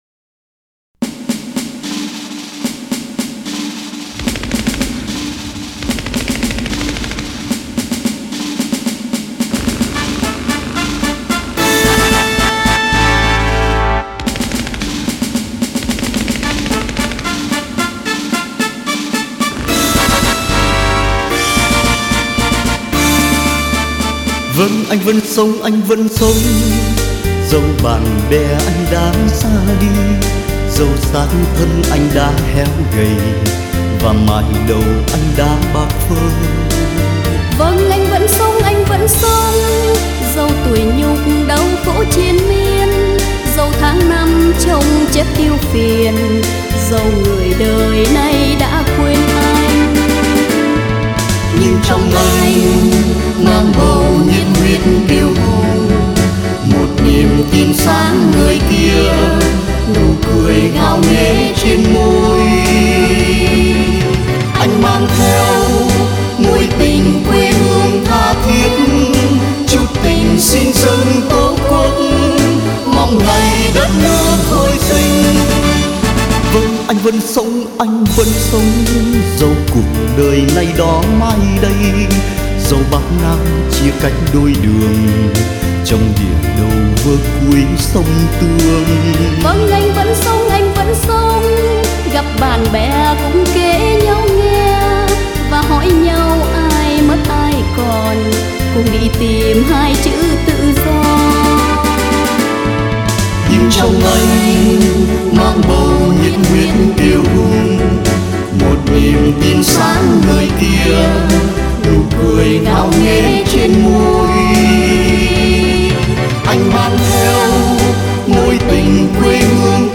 Tù khúc Anh Vẫn Sống được sáng tác tháng 7 năm 1981 tại trại Z30D Hàm Tân, khi nhìn thấy cảnh những bạn tù vượt trốn bị bắt lại trên đường dẫn về trại.